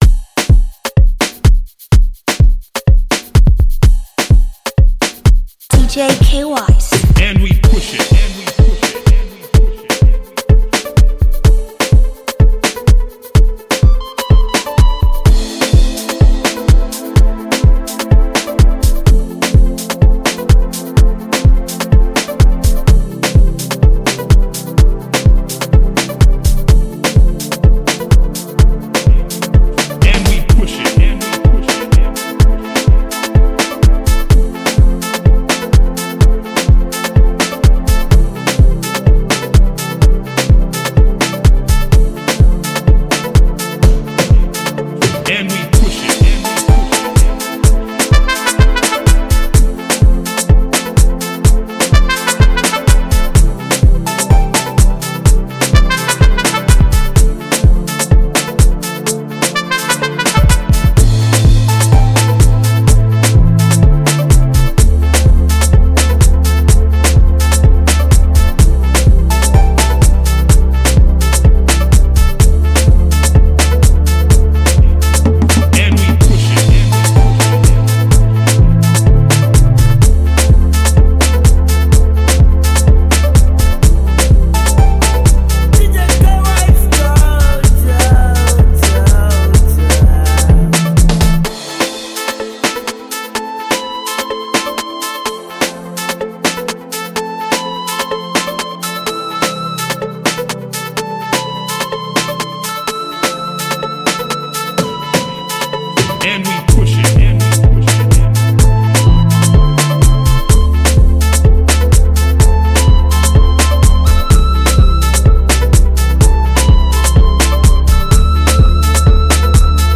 House rhythm